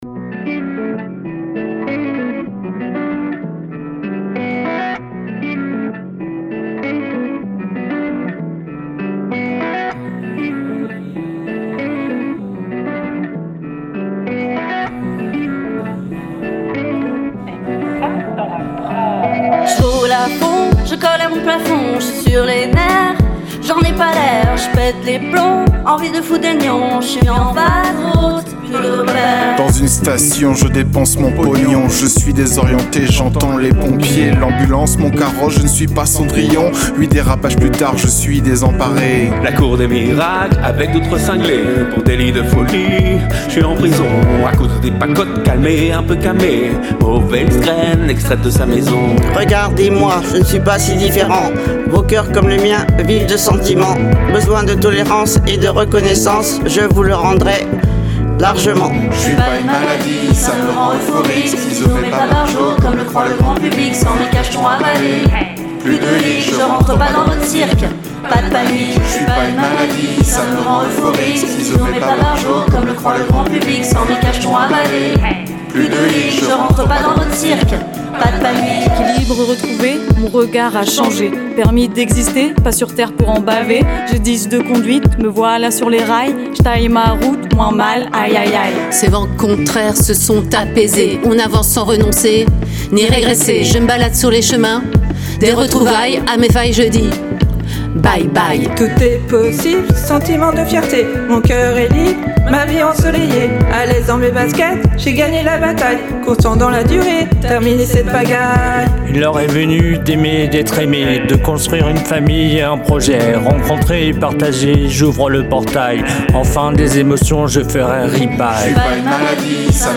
Le morceau s’appelle « Délit de folie », il a été créé dans le cadre d’un projet culture urbaine aux influences Hip-Hop et en partenariat avec l’association DMZ qui œuvre pour la démocratisation des pratiques musicales.